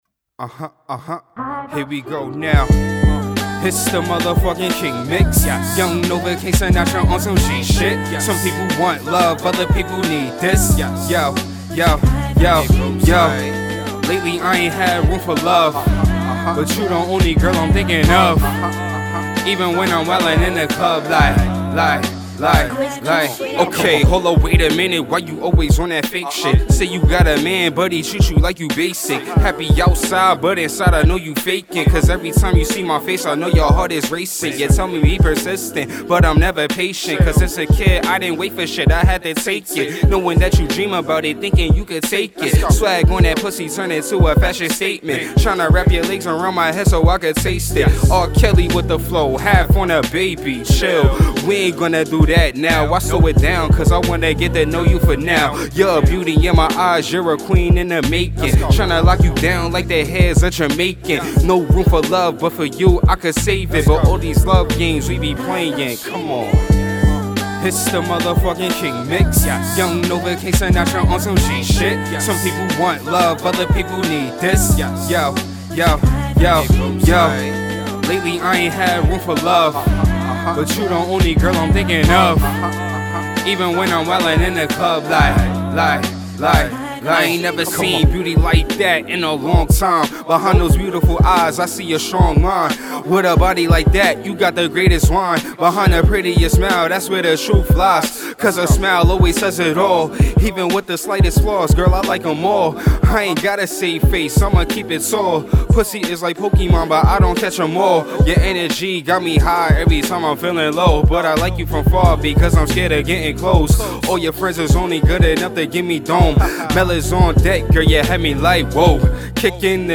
Hiphop
With Smooth Wordplay On A Beat